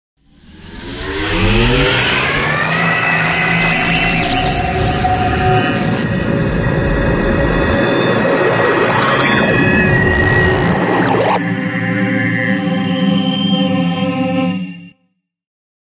科幻小说效应 21
Tag: 未来 托管架 无人驾驶飞机 金属制品 金属 过渡 变形 可怕 破坏 背景 游戏 黑暗 电影 上升 恐怖 开口 命中 噪声 转化 科幻 变压器 冲击 移动时 毛刺 woosh 抽象的 气氛